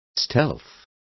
Complete with pronunciation of the translation of stealth.